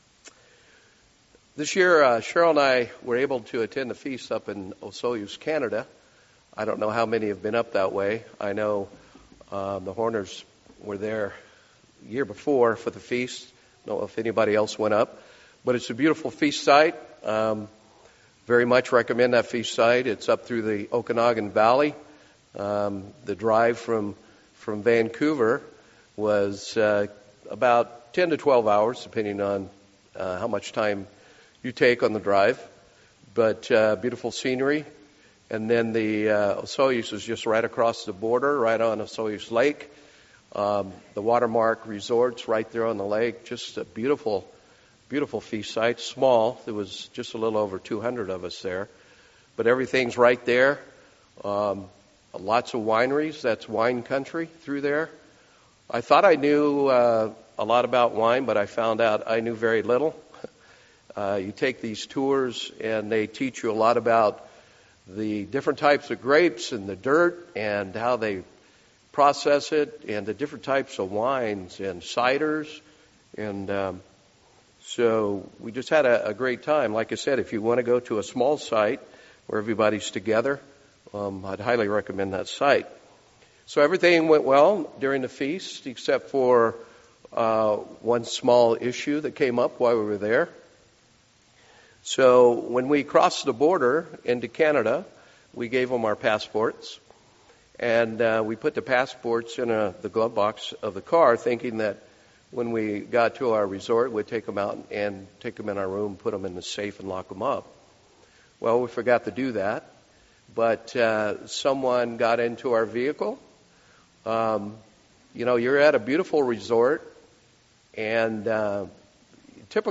Why is understanding our true identity so important for unity in God's church? Let's explore these questions today in this Sabbath message.